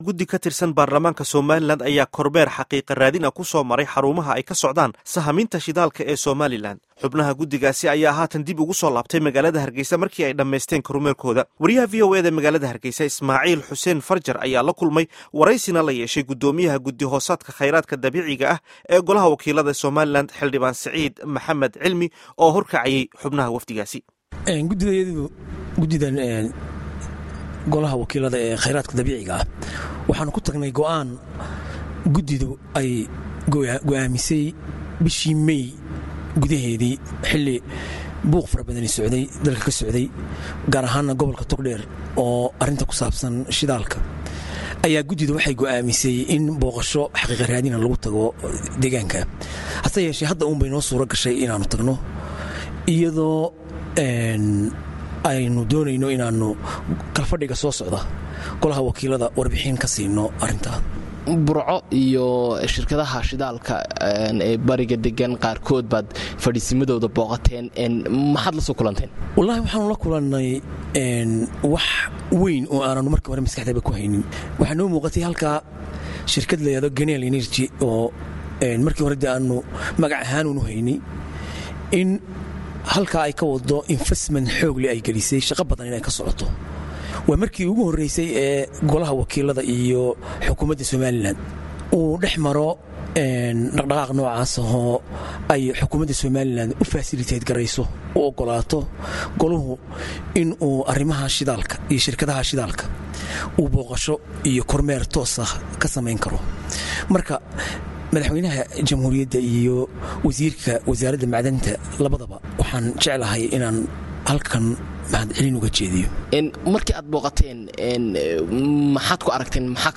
Waraysiga Xildhibaanka ee arrimaha Shidaalka Somaliland